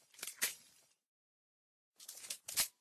trachelium_reload.ogg